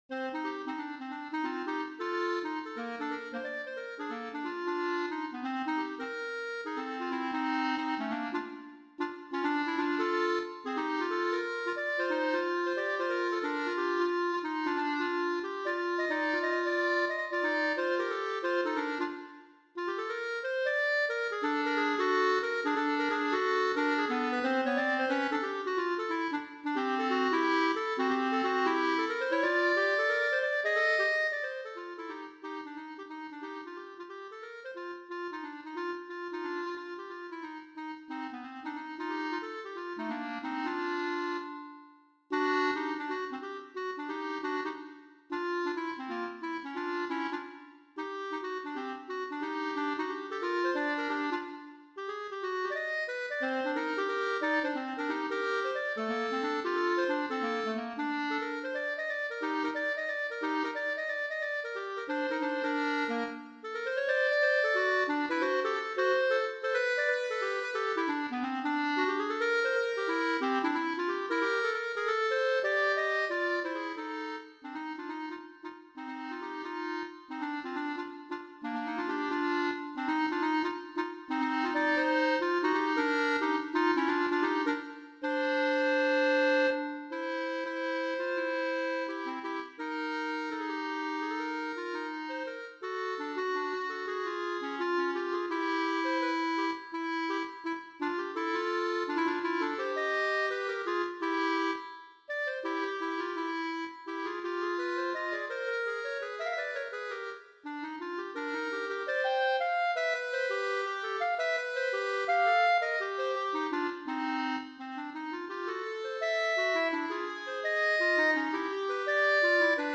Gattung: Für 2 Klarinetten